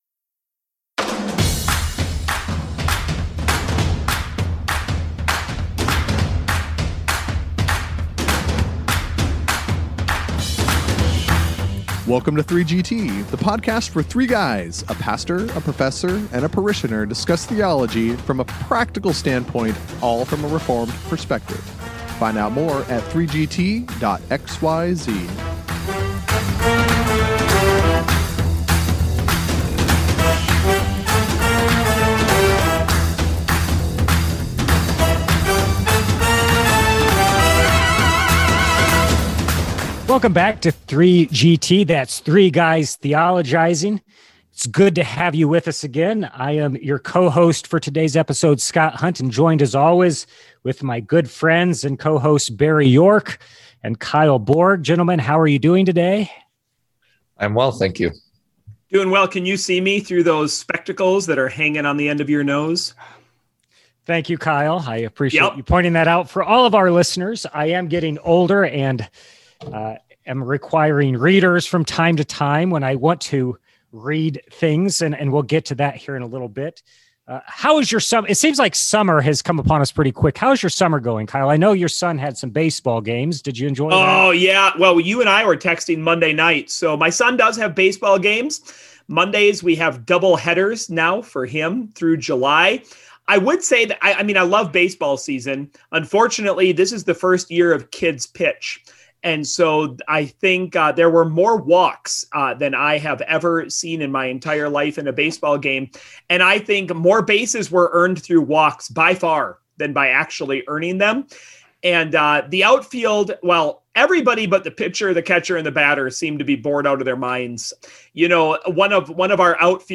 In this latest episode of Three Guys Theologizing, the parishioner asks a question to the preacher and the homiletics professor on a listener’s behalf.